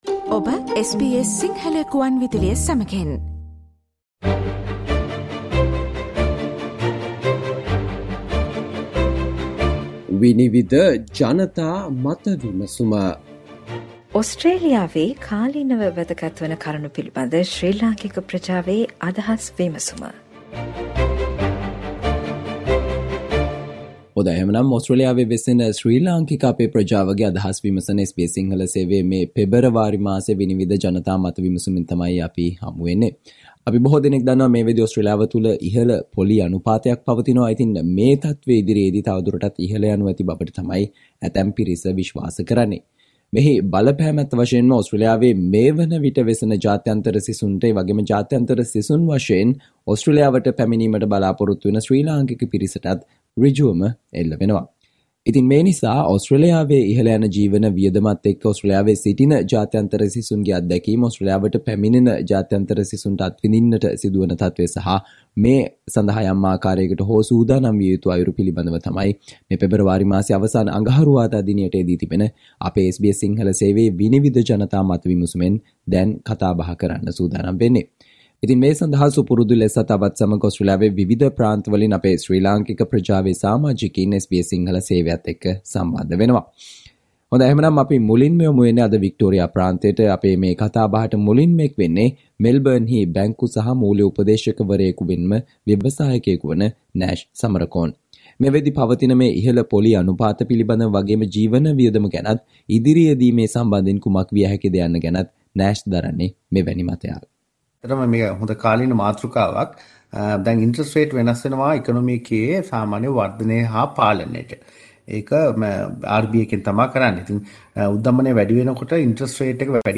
Listen to ideas from the members of Sri Lankan community in Australia talking about the experiences of international students with the rising cost of living , the situation that people hoping to come to Australia may face and how to prepare for it in some way
Panel discussion